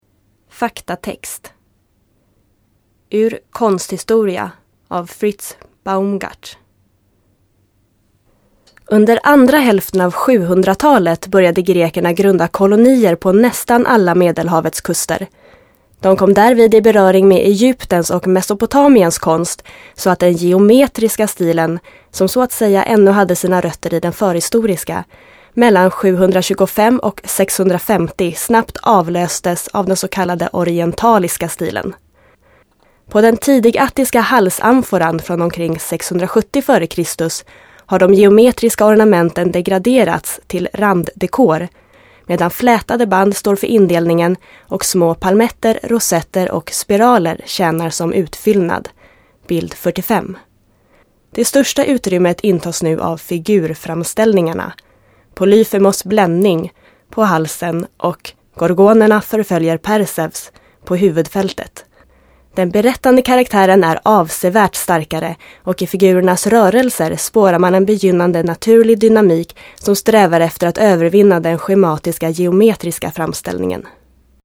voice over demo (swedish)
nonfiction (in swedish)